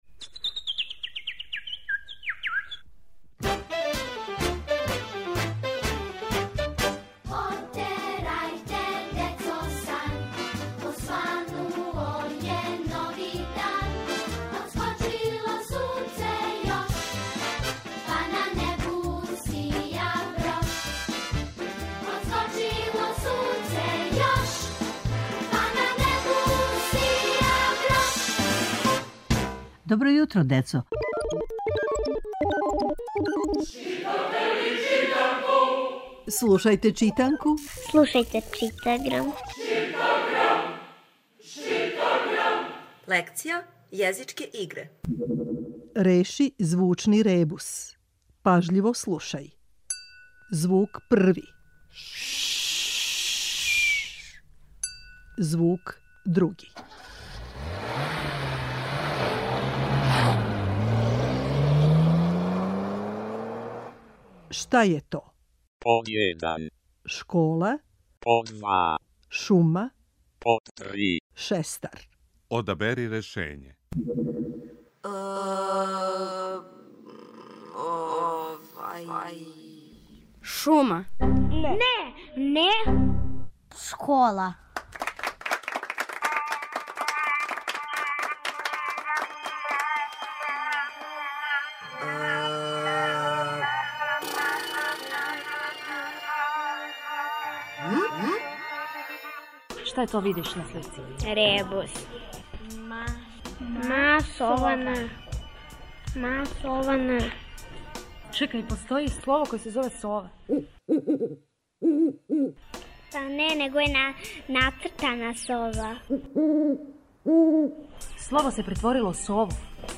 Читаграм је читанка која се слуша. Слушамо лекцију из читанке за 1. разред.